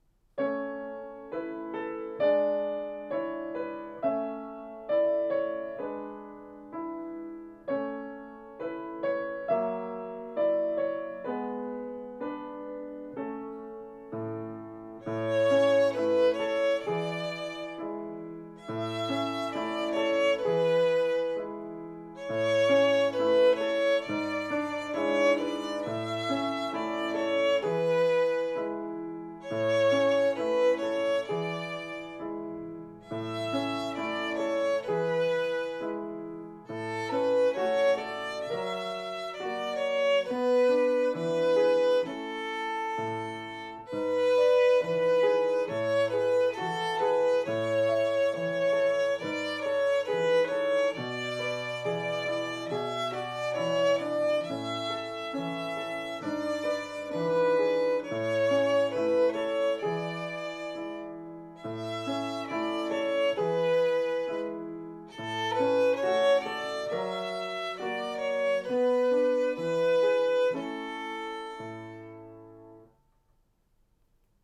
♪♪♪練習のヒント♪♪♪ 一つ一つの音が豊かに響くように、ゆっくり丁寧に弾きます。
ご自宅での練習用に録音しました。